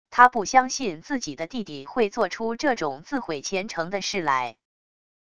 她不相信自己的弟弟会做出这种自毁前程的事来wav音频生成系统WAV Audio Player